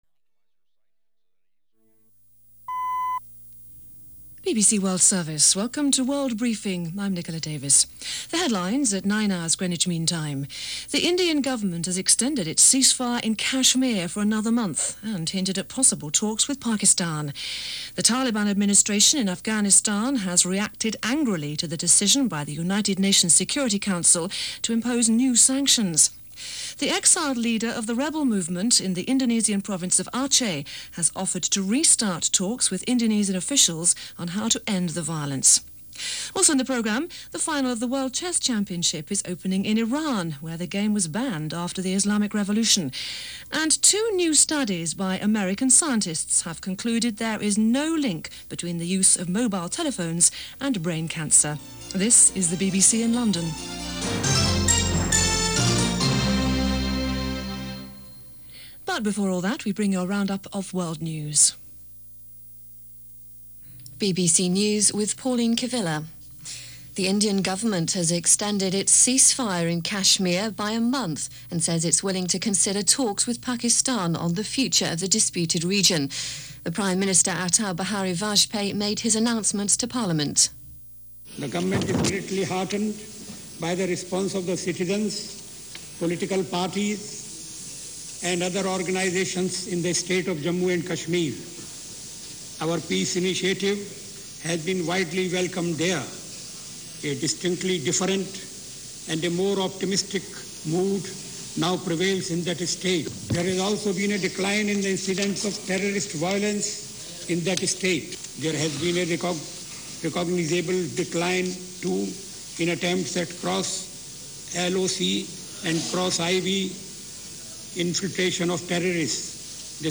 A busy day in India, Afghanistan, Iran and in fact, the rest of the world, as presented by the BBC World Service on December 20,2000